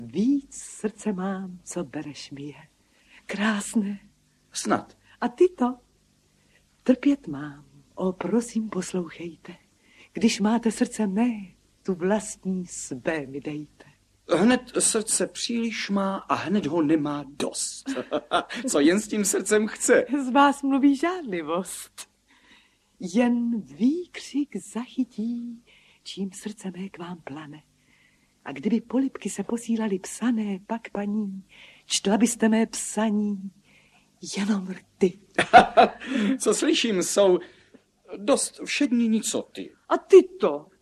Cyrano z Bergeracu audiokniha
cyrano-z-bergeracu-audiokniha